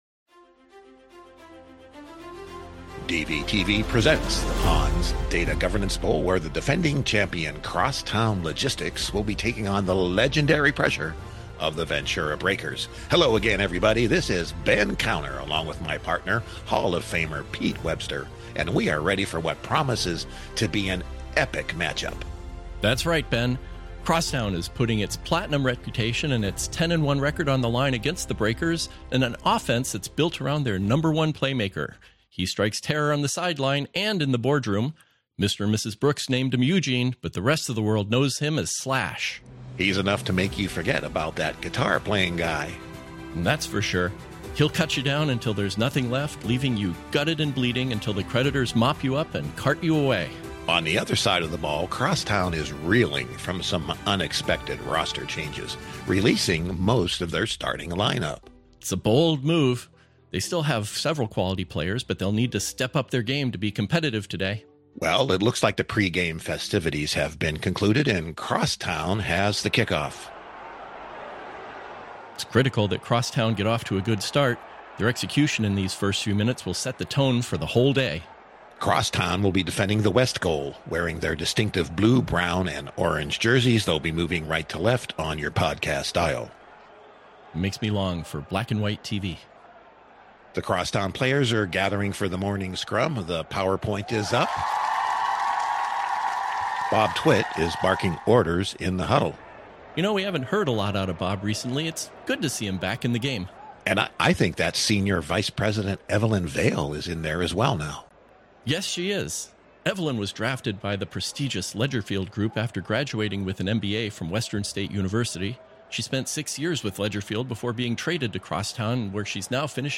Sometimes we got talking with our guests and next thing you know we've gone long or ventured into a topic that's beyond the scope of the episode.